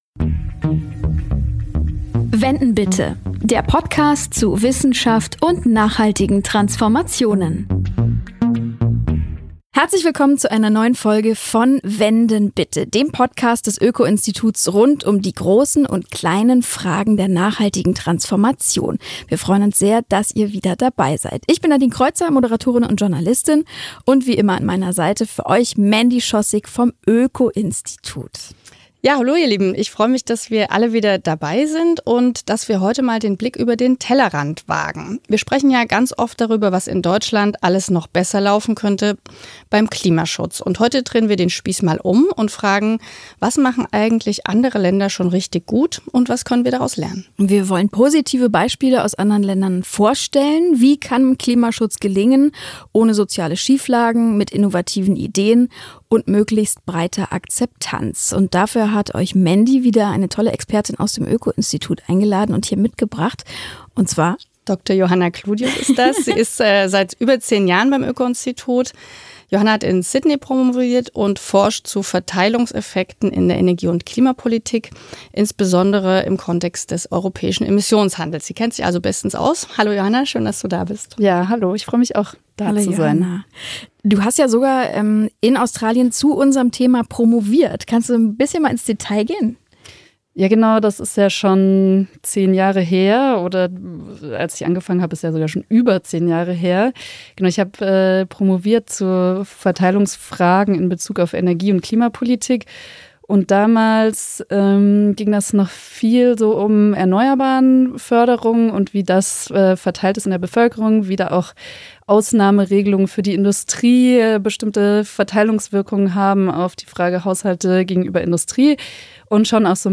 Wir interviewen Wissenschaftler*innen des Öko-Instituts und fragen, wo ein Thema aus Nachhaltigkeitssicht aktuell steht, welche Herausforderungen es auf dem Weg zu mehr Umwelt-, Klima- und Ressourcenschutz gibt und wie die Zukunft gestaltet werden kann.